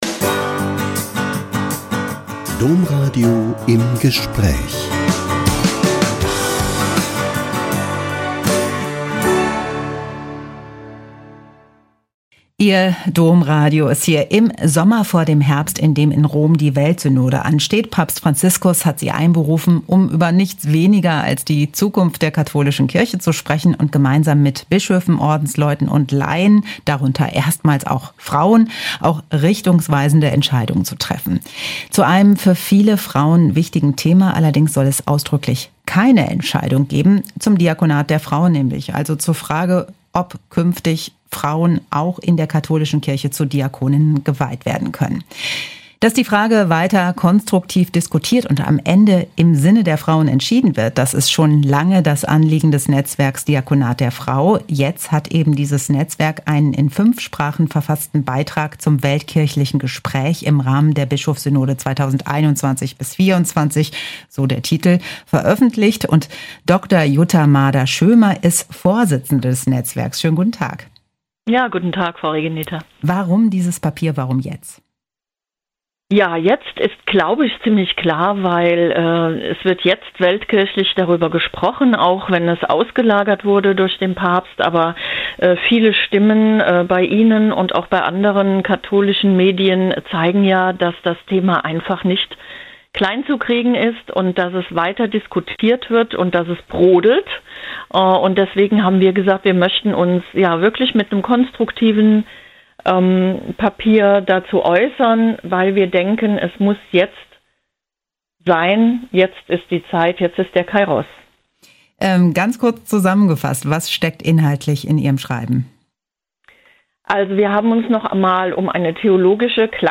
Handreichung zum "Diakonat der Frau" veröffentlicht - Ein Interview